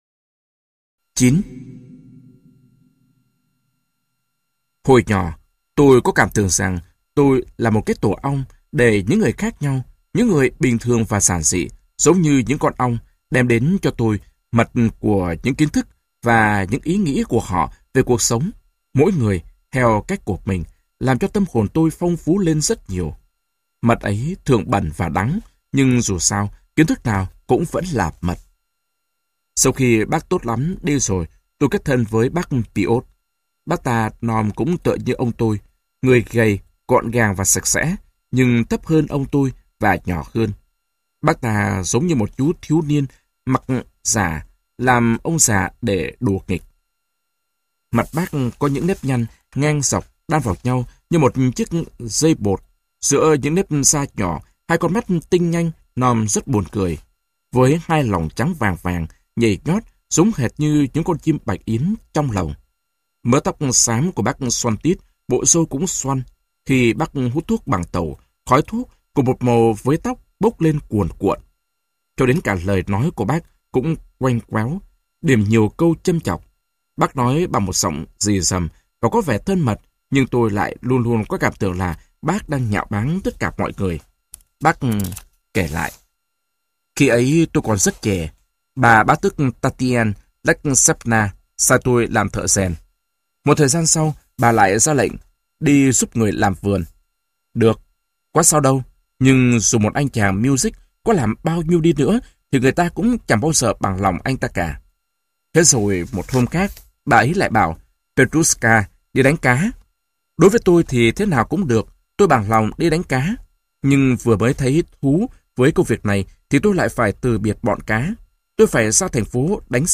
Sách nói Thời Thơ Ấu - Macxim Gorki - Sách Nói Online Hay